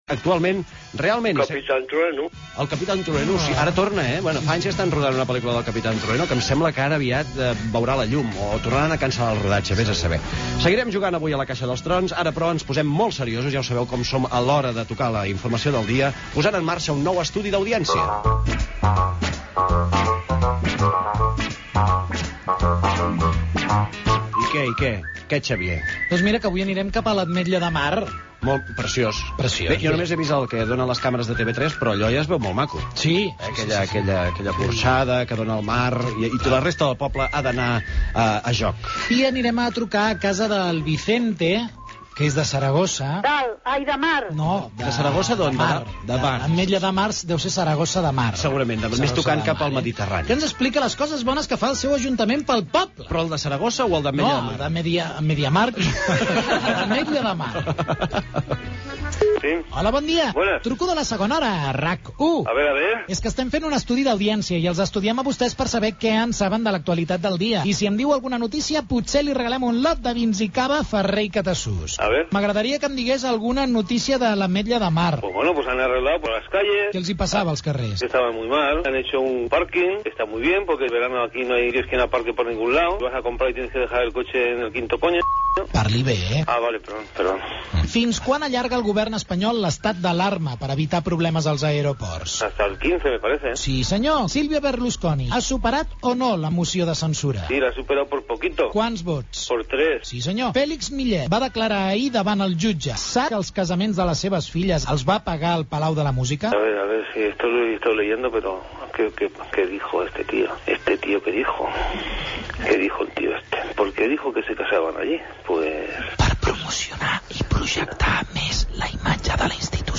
El programa La Segona hora de Rac1 ha entrevistat aquest matí a un resident a l'Ametlla de Mar.